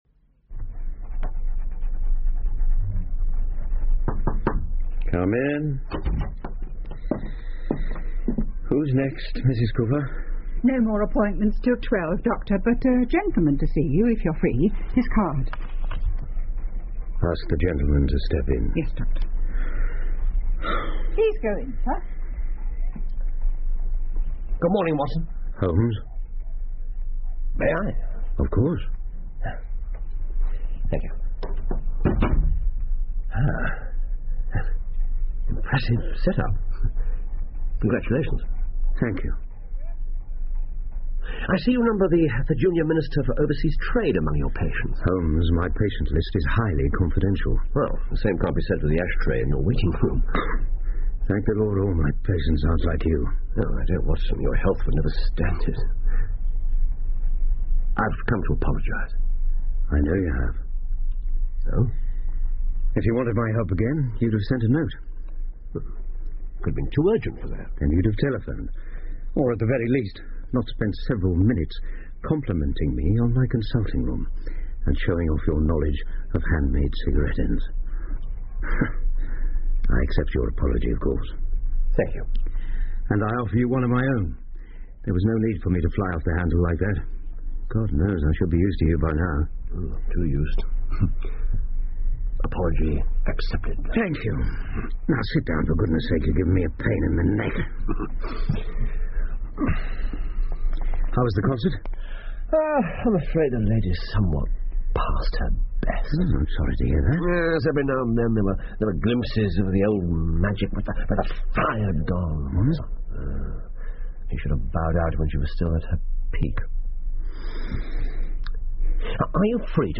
福尔摩斯广播剧 The Retired Colourman 6 听力文件下载—在线英语听力室